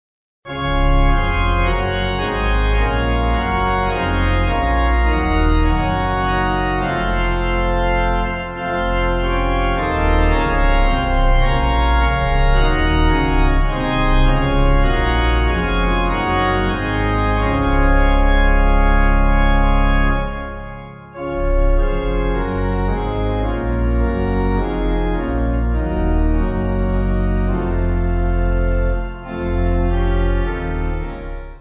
(CM)   5/Db